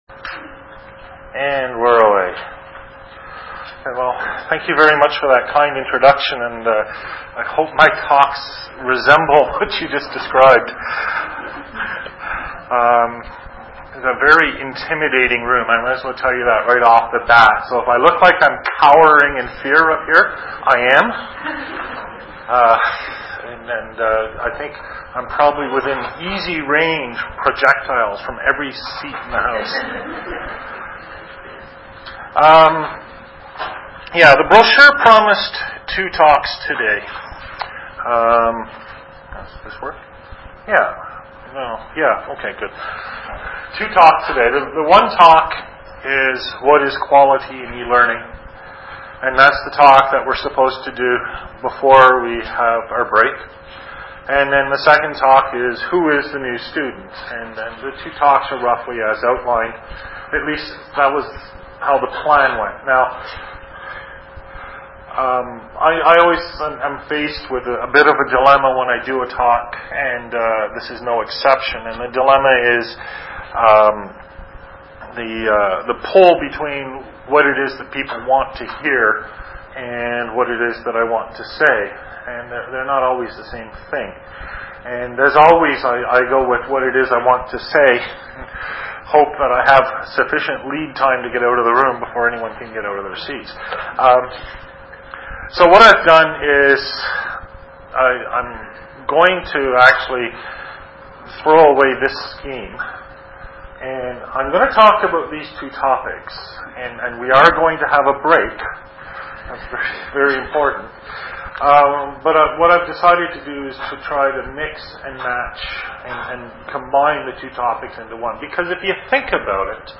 Invited Lecture, Australian Defense Force Academy (ADFA), Canberra, ACT, Australia, Lecture, Sept 16, 2004.